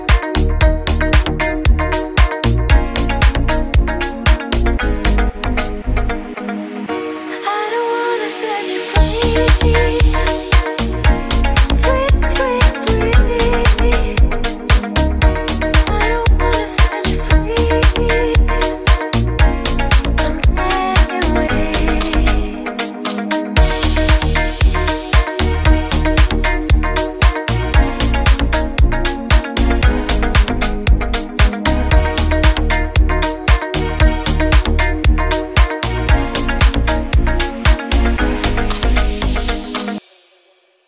• Качество: 320, Stereo
женский вокал
deep house
чувственные
приятные
красивый женский голос